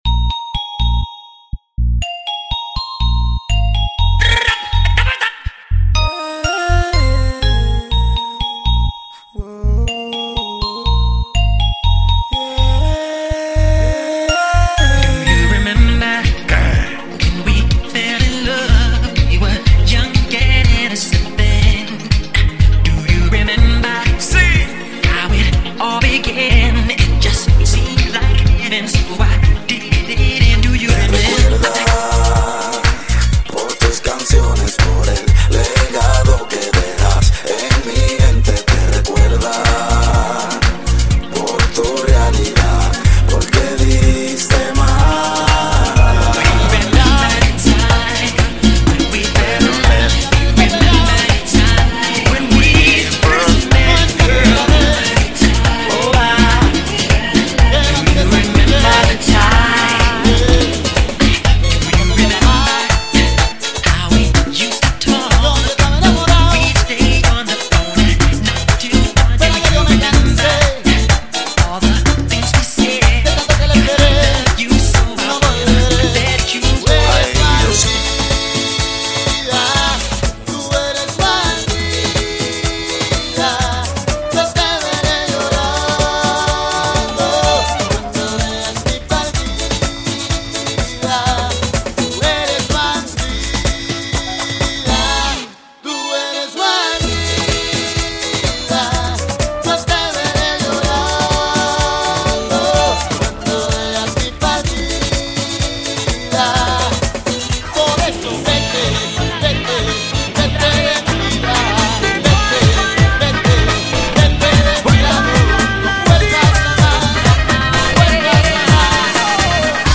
Sample Tracks from this Exclusive Non-Stop MERENGUE MIX CD: